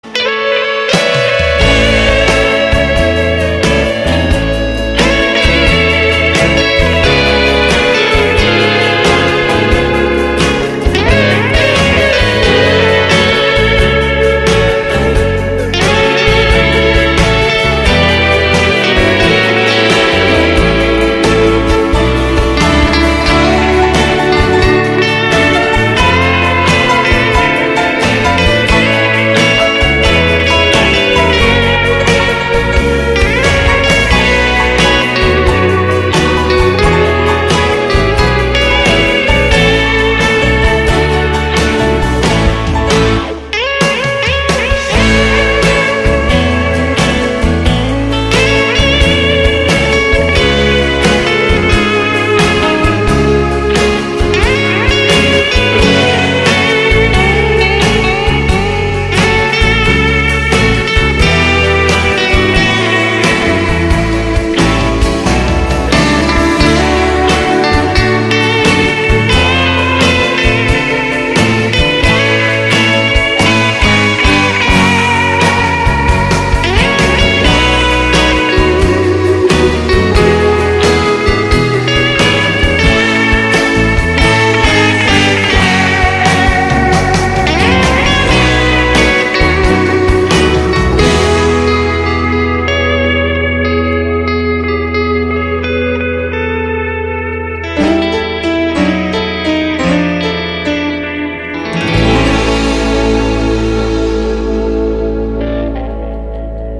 guitar and piano